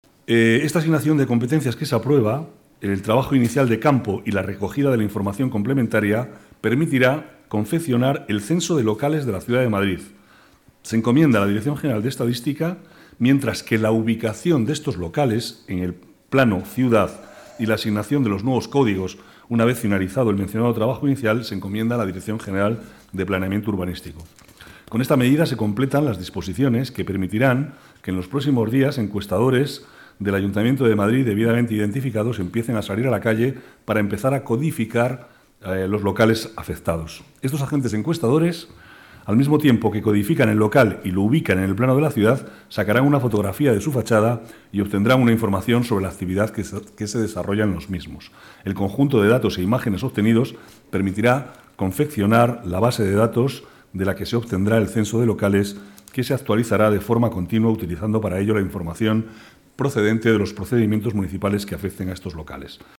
Nueva ventana:Declaraciones vicealcalde, Manuel Cobo: censo de locales